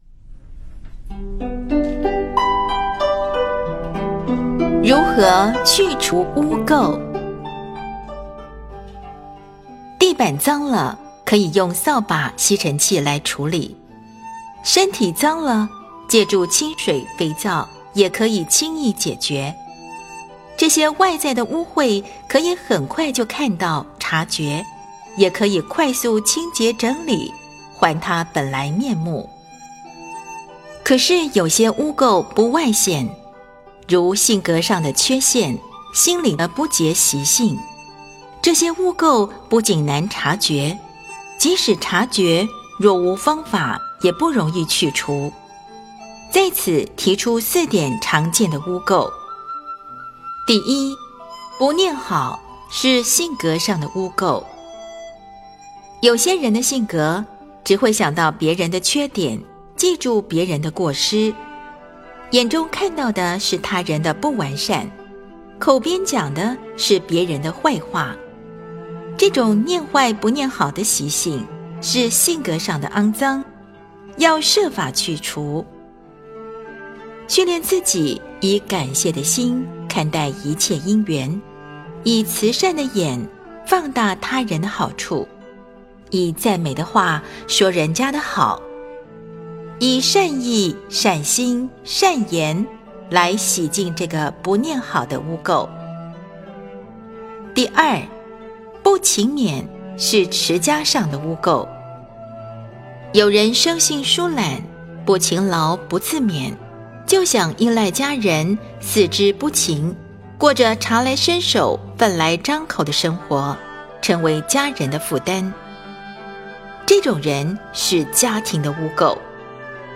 如何去除污垢 诵经 10. 如何去除污垢--佚名 点我： 标签: 佛音 诵经 佛教音乐 返回列表 上一篇： 09.